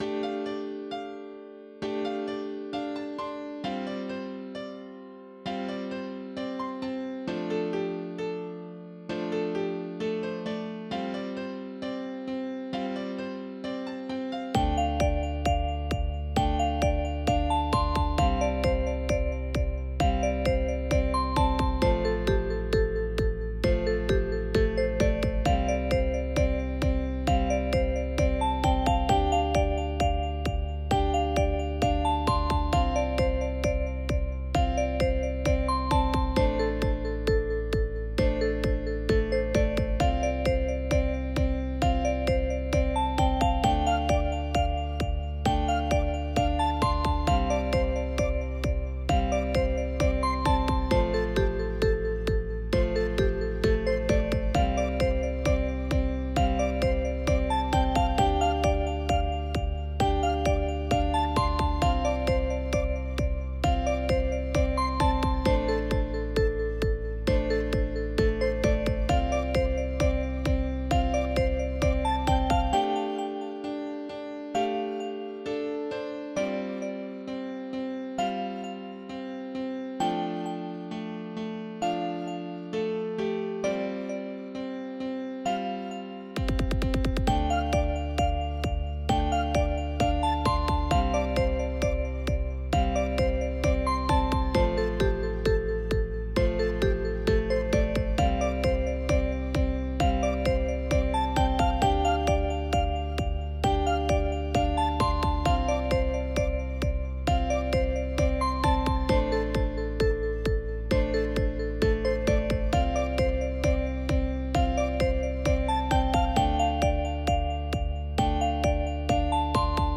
neo wave game techno song
bass drum